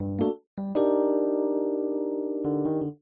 钢琴爵士乐循环播放
描述：温暖的爵士钢琴，微弱的罗德琴声。
Tag: 140 bpm Jazz Loops Piano Loops 1.15 MB wav Key : C